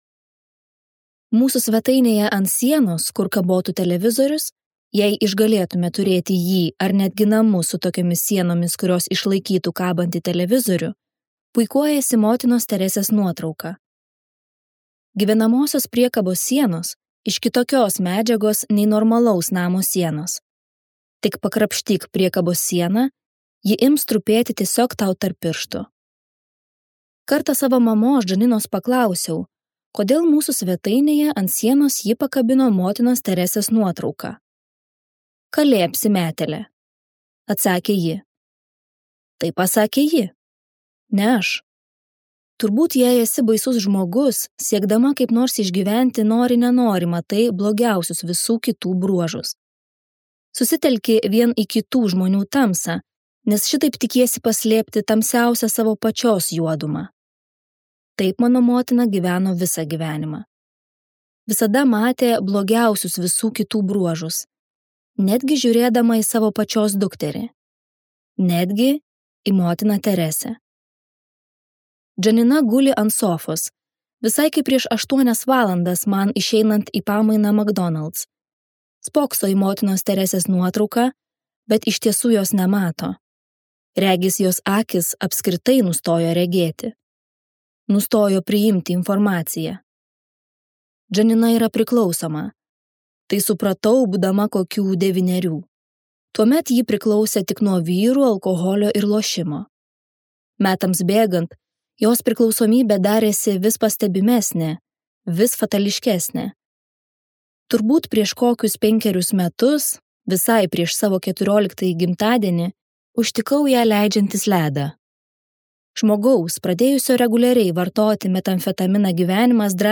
Naujoji autorės Colleen Hoover audioknyga „Širdies kauleliai“. Tai jautri istorija apie savęs paiešką ir meilę.